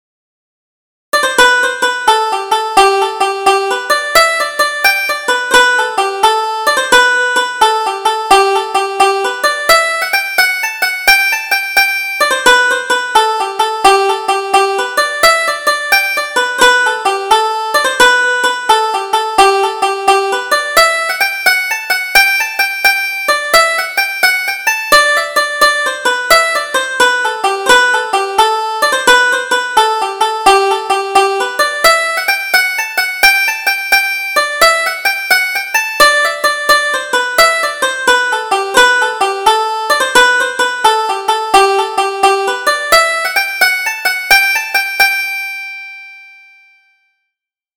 Double Jig: The Runaway Bride